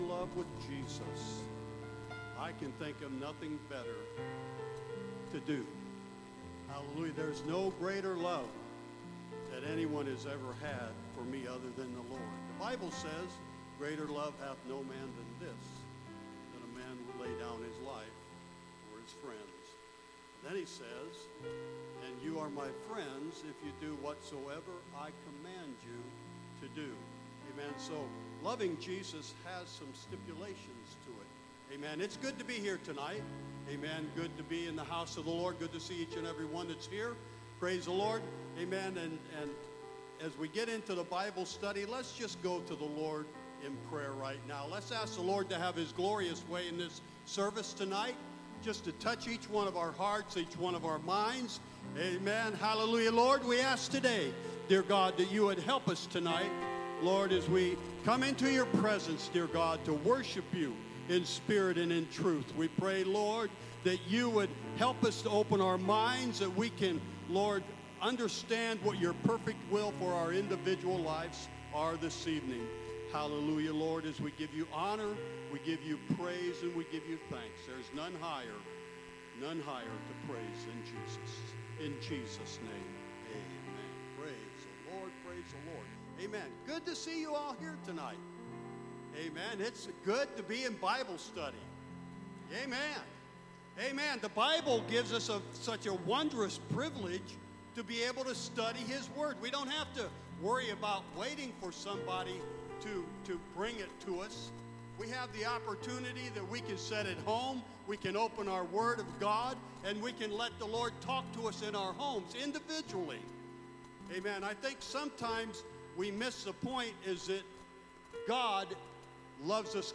Sermons | Elkhart Life Church